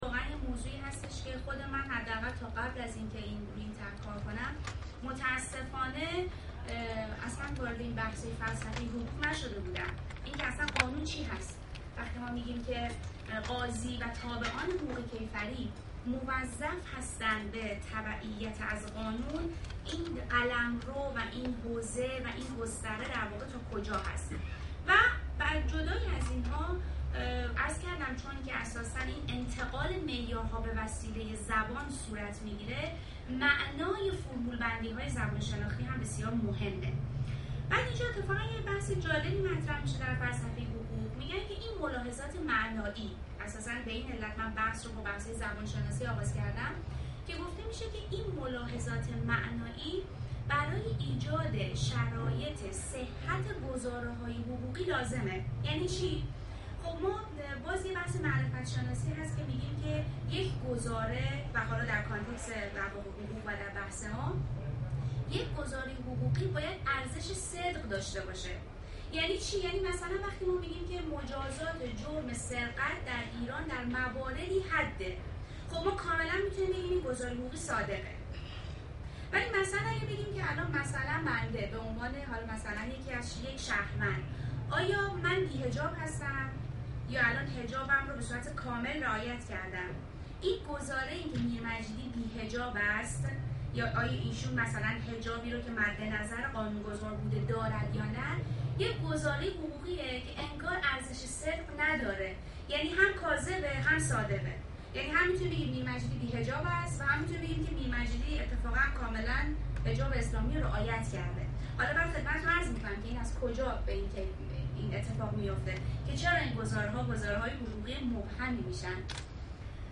پژوهشکده مطالعات تطبیقی حقوق برگزار می کند: